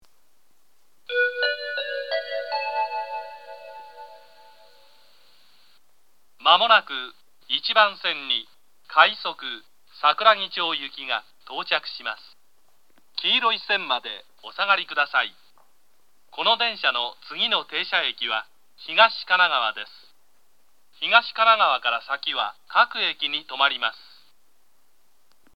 東海道型(男性)
接近放送